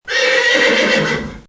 B_JUMENT.mp3